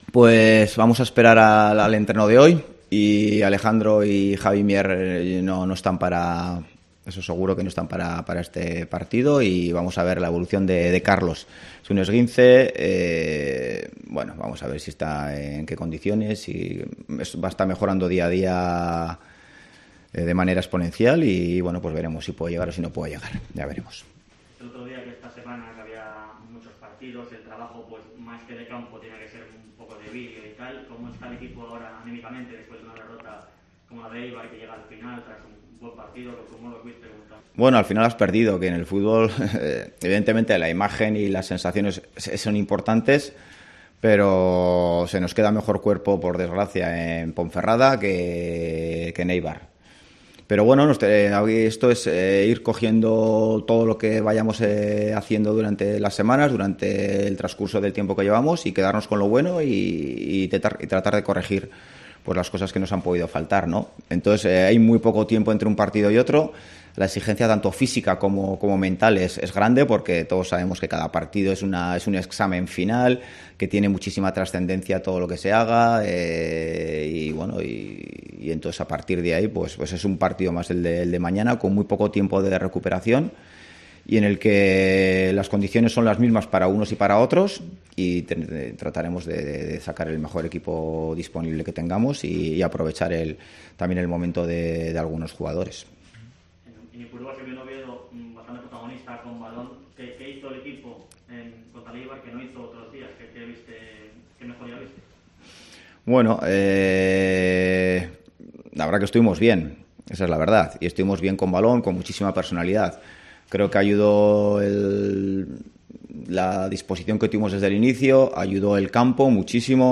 Rueda de prensa Ziganda (previa Oviedo-Las Palmas)